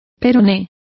Complete with pronunciation of the translation of fibula.